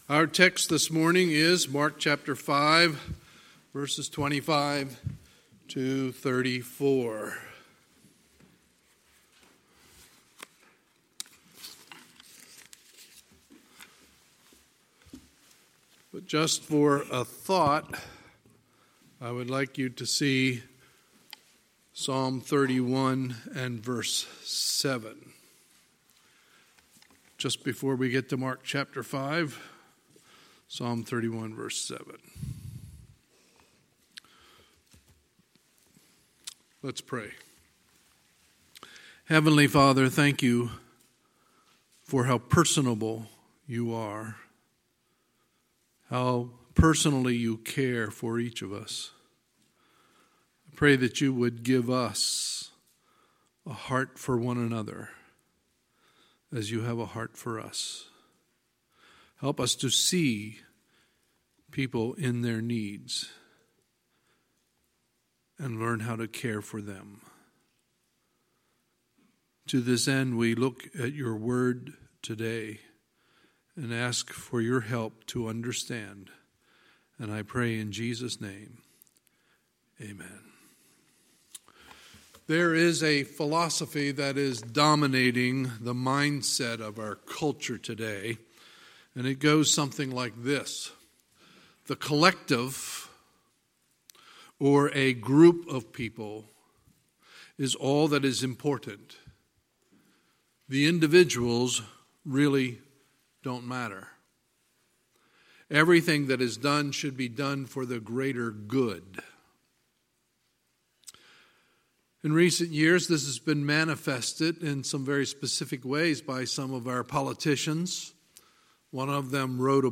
Sunday, May 5, 2019 – Sunday Morning Service